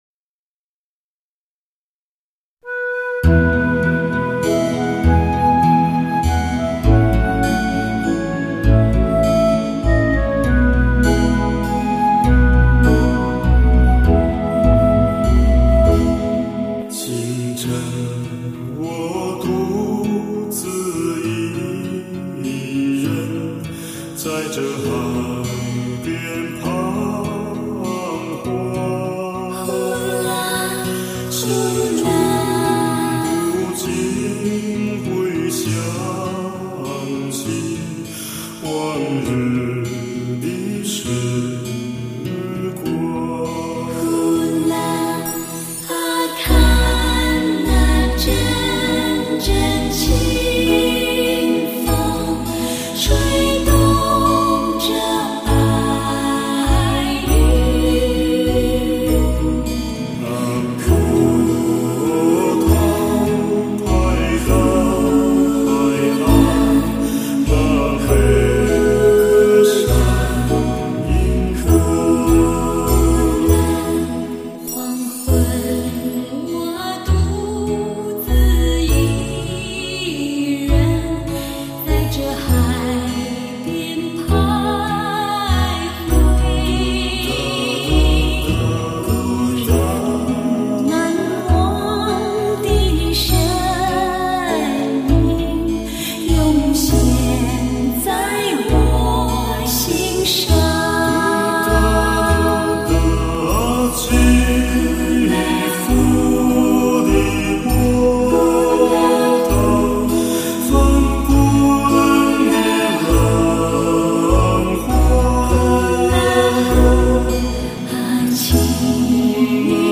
类型: HIFI试音
与音乐同舞，让心随着纯净的歌声，重温往日那如歌往事。